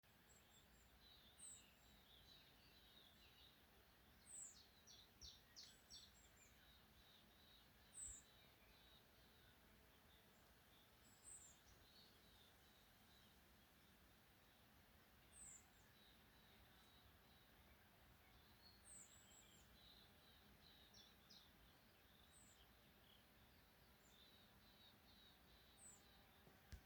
Птицы -> Овсянковые ->
обыкновенная овсянка, Emberiza citrinella